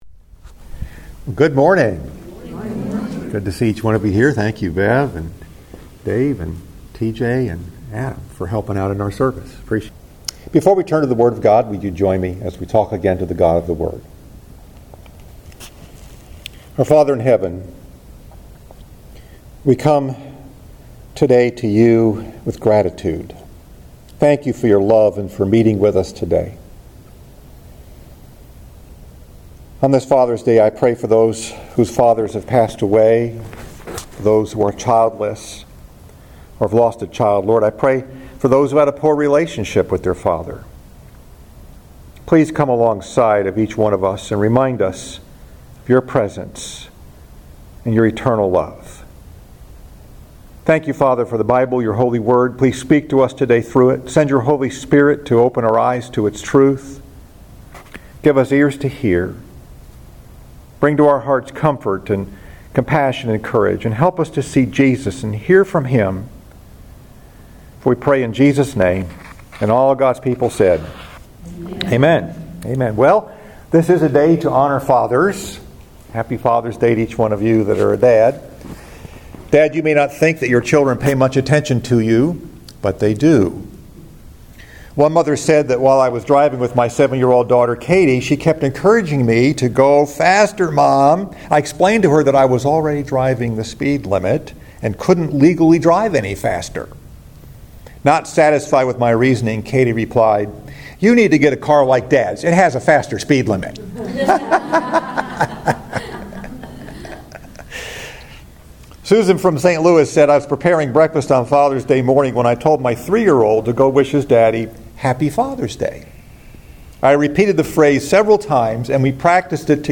Message: “A Father’s Footsteps” Scripture: Psalm 128 Father’s Day Sunday Celebration